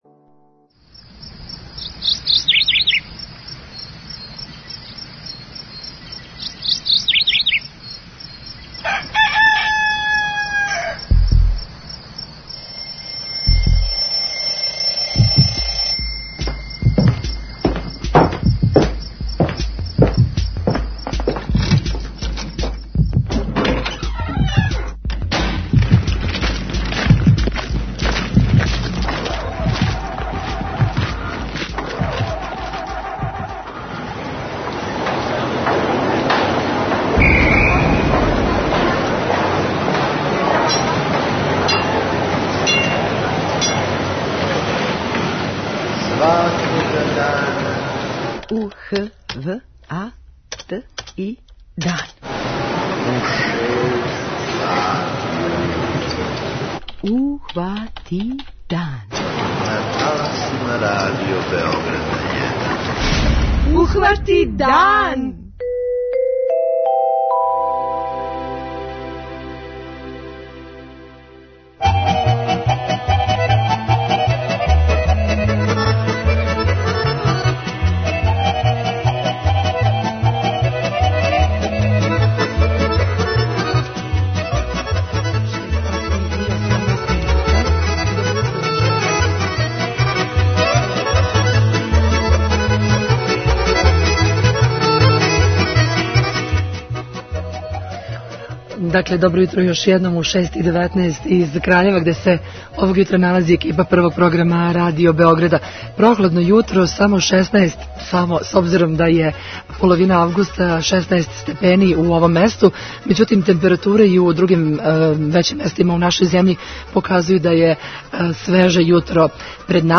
Јутарњи програм емитујемо уживо из Краљева!